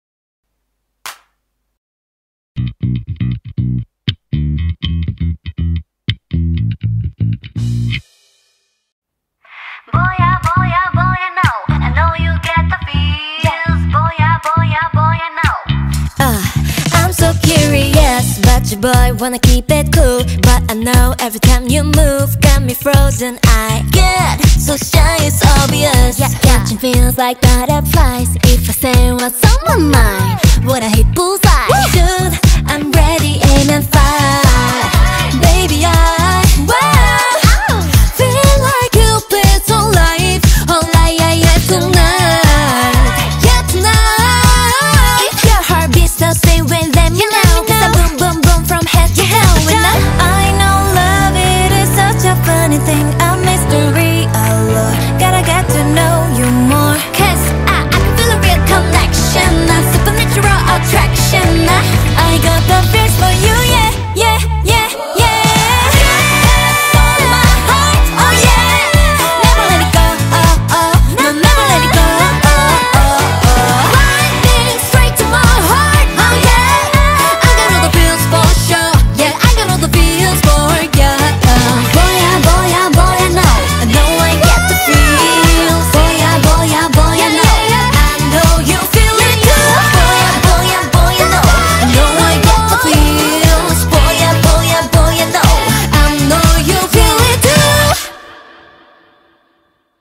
BPM120
Audio QualityCut From Video